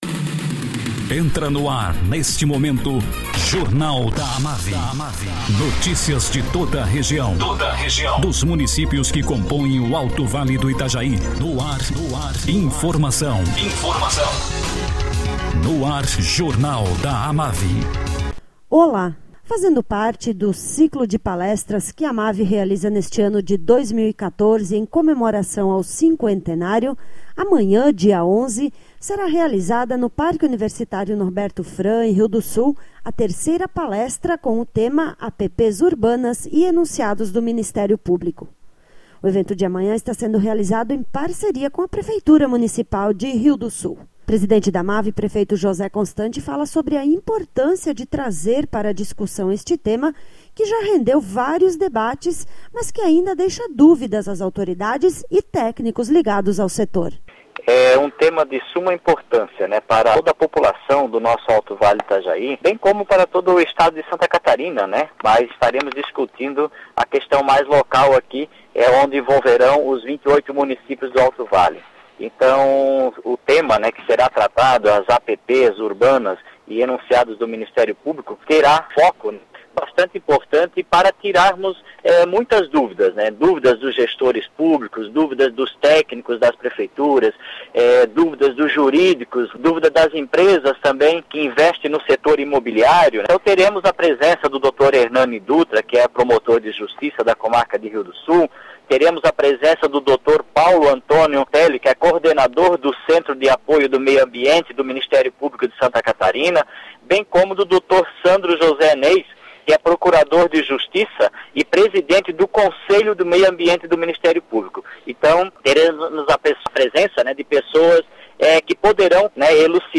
Presidente da AMAVI, prefeito José constante, fala sobre a palestra que será realizada amanhã, com o tema APPs Urbanas. O evento acontece no Parque Universitário Norberto Frahm, em Rio do Sul, com início às 9h.